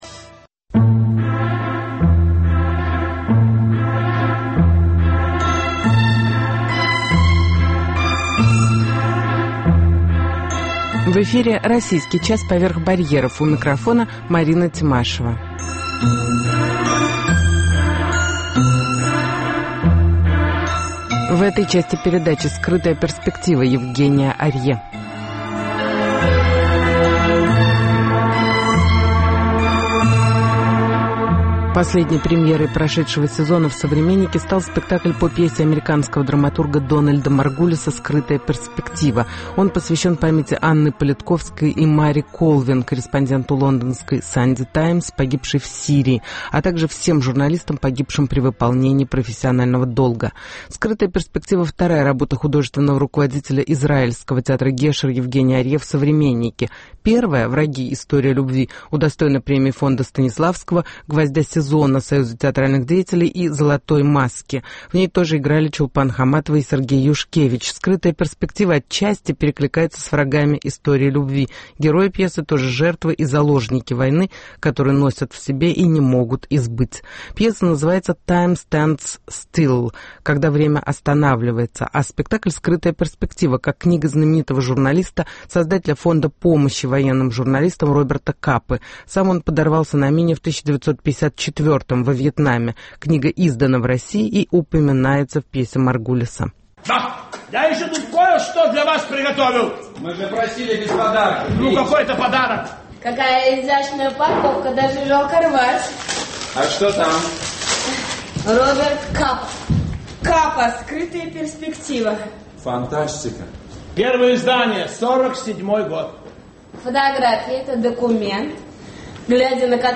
Интервью с Евгением Арье